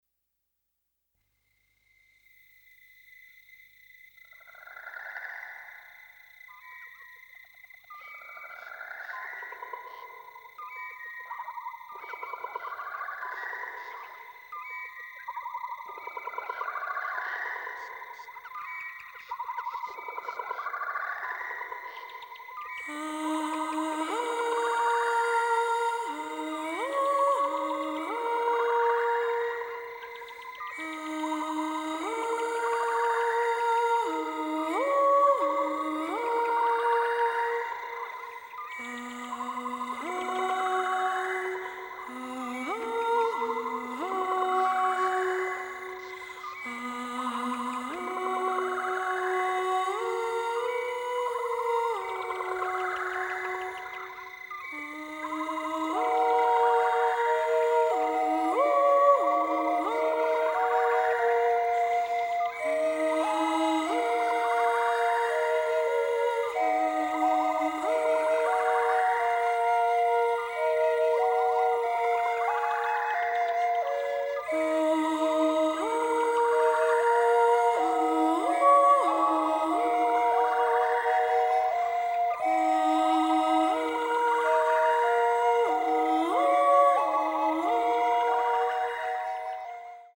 original motion picture score